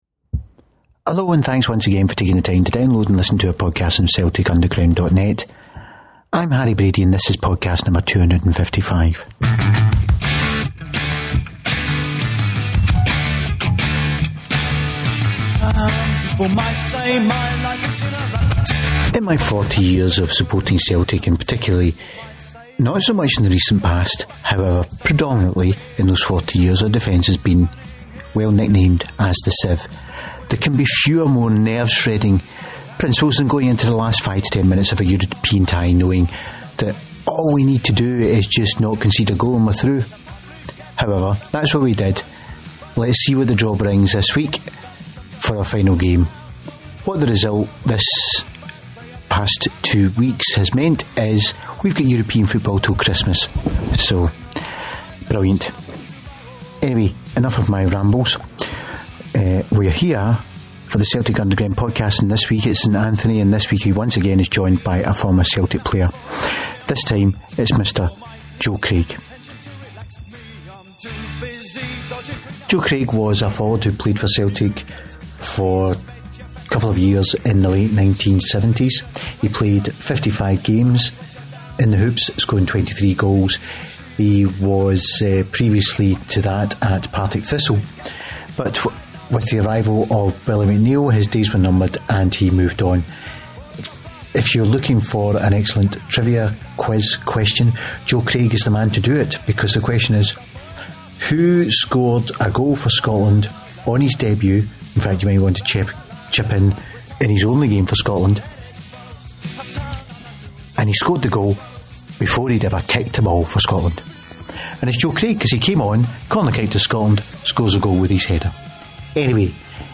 in conversation with another former Celtic hero